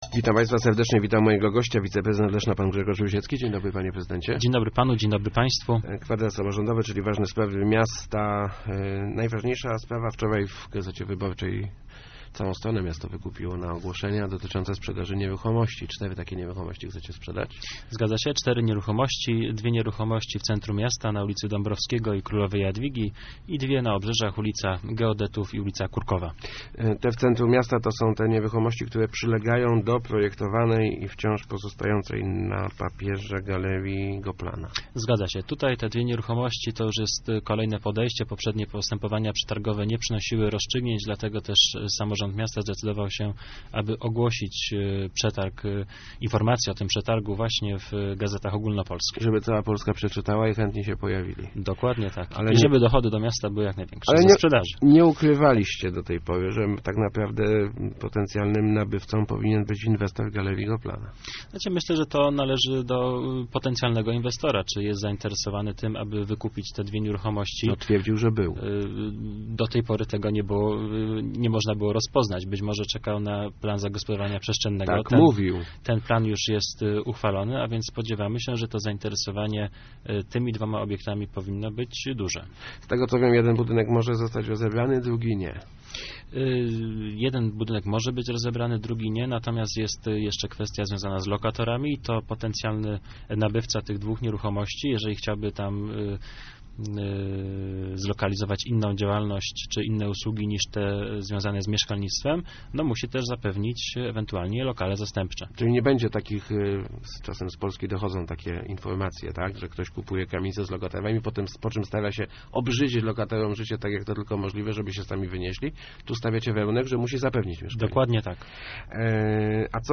Gościem Kwadransa był wiceprezydent Grzegorz Rusiecki ...